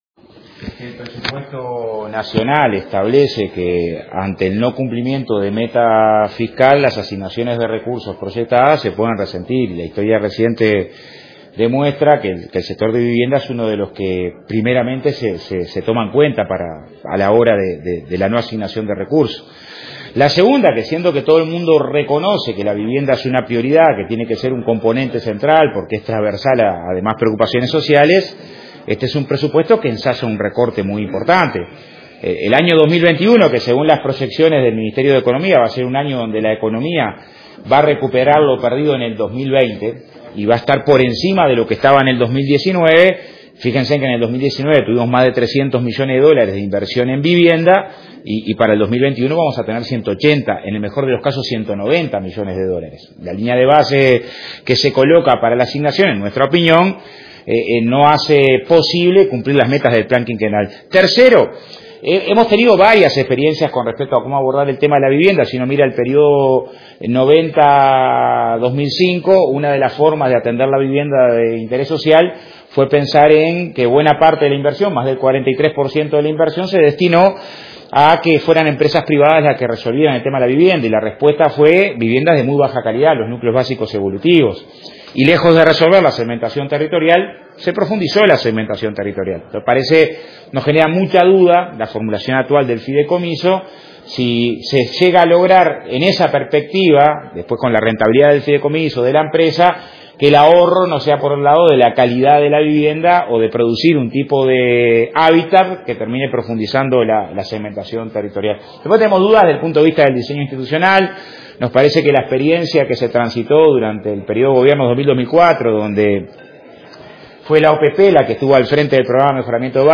En declaraciones a la prensa, el legislador señaló que en el año 2019  hubo más de 300 millones de inversión en vivienda y para el 2021 se tendrá 190 millones en el mejor de los casos.
Audio con las declaraciones disponible para descarga